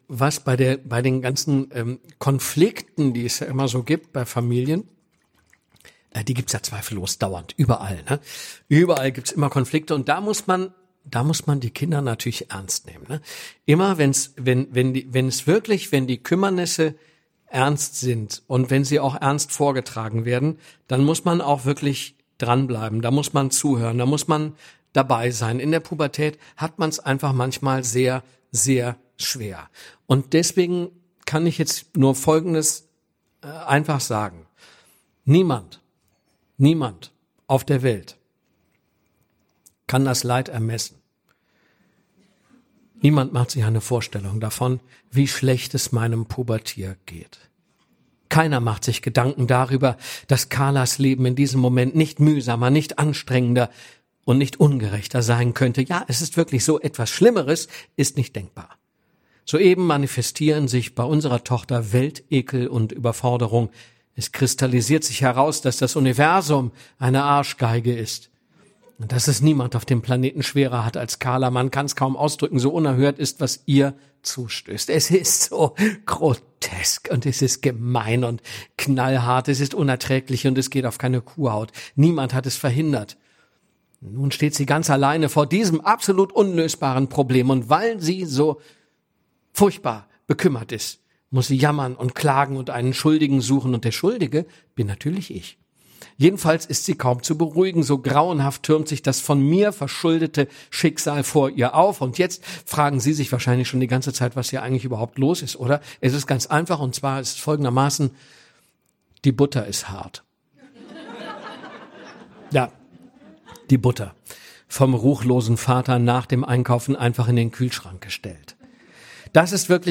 Hörbuch: Das Beste!